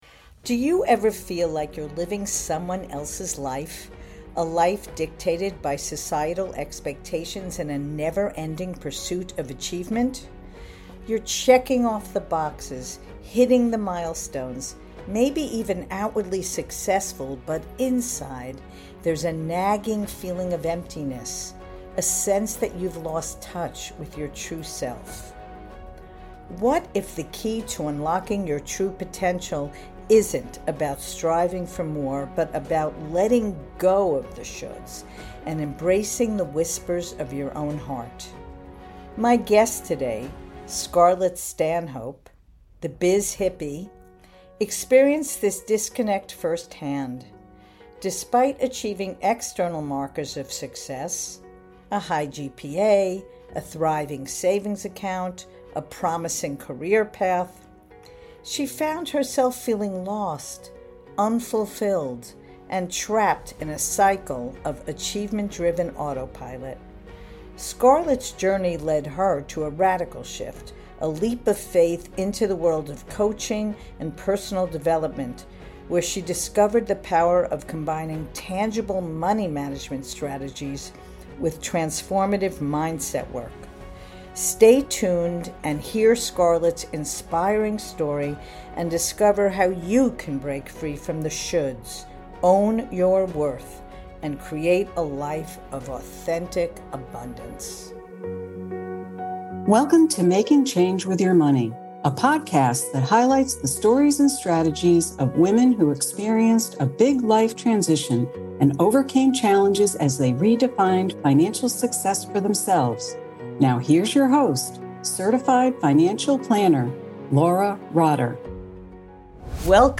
How To Transform Your Relationship With Money: An Interview